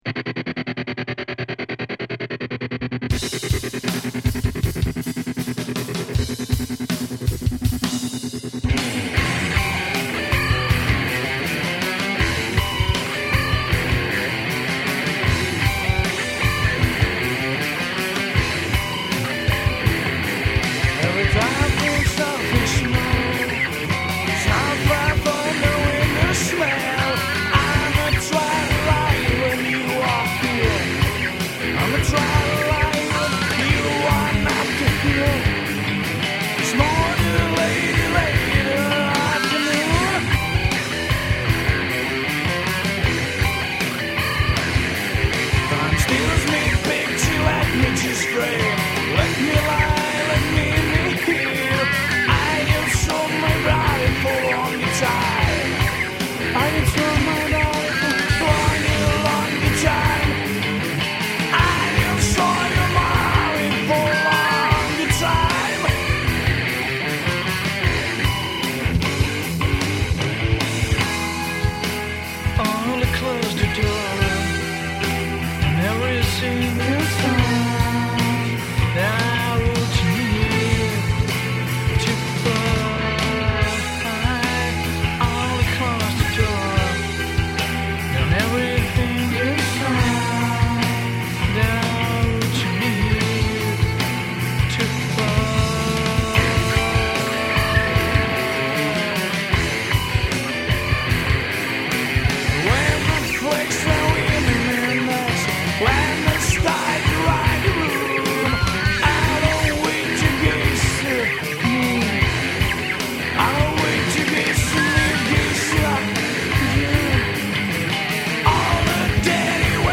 vocal, guitar
drums
bass